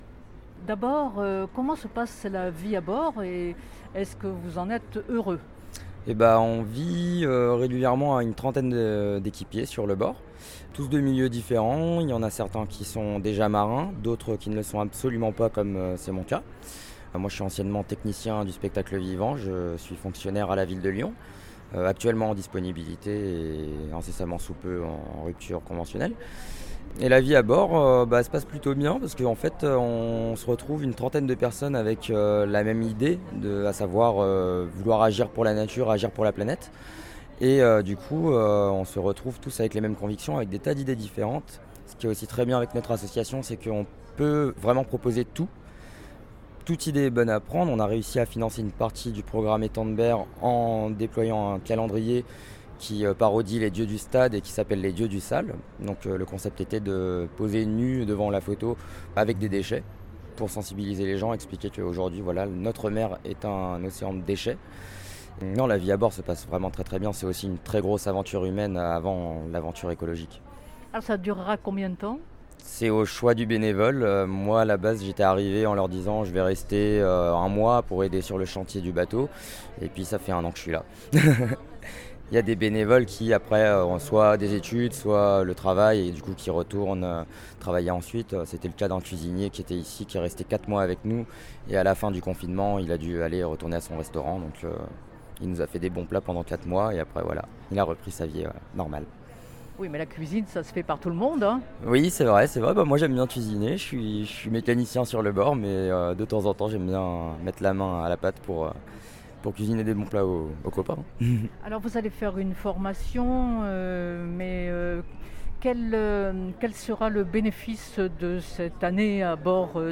Retour sur…(3/4) Congrès mondial de la nature. Entretien